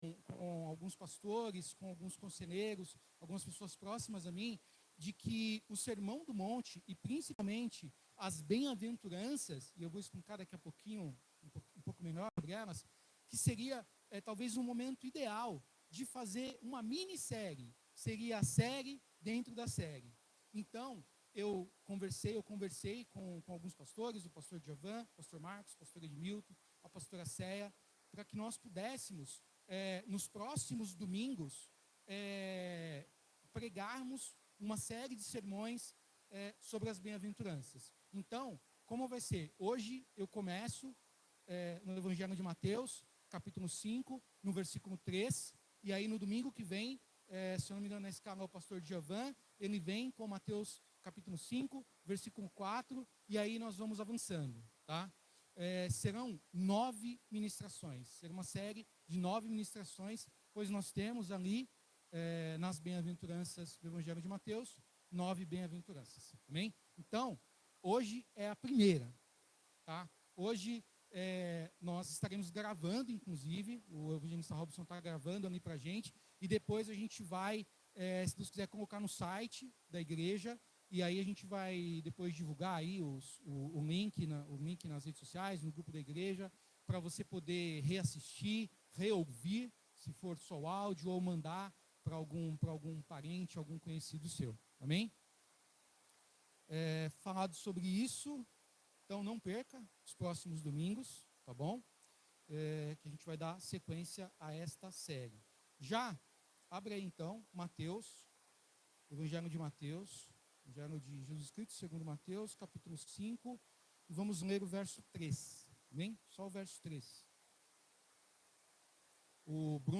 Sermao-Bem-aventurado-os-pobres-em-espirito.mp3